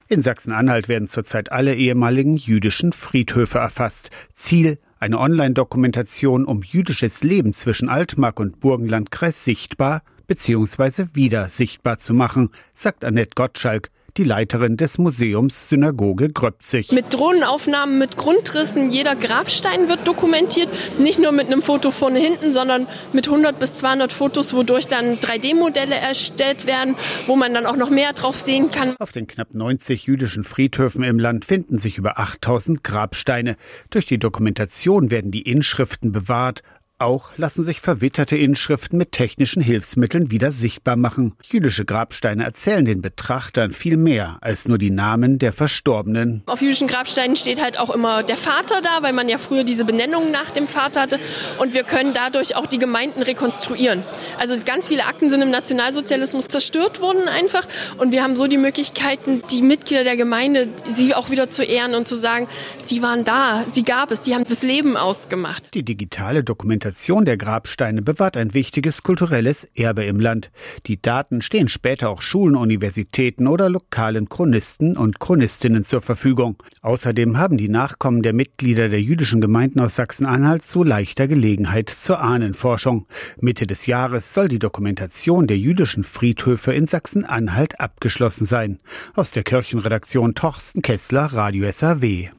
radio SAW 22.01.2026 04:58 Dokumentation
Interviewte